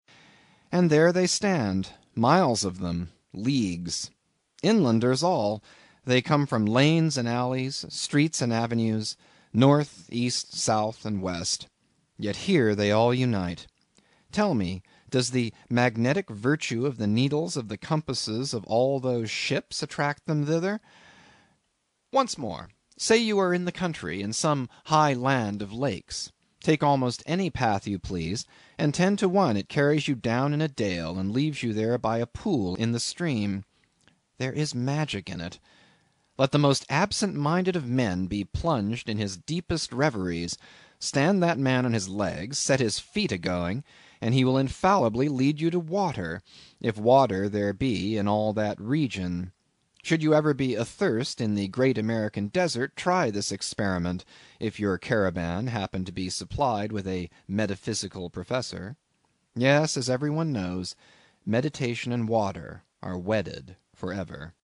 英语听书《白鲸记》第169期 听力文件下载—在线英语听力室